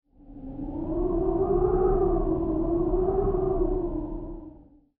windgust2.wav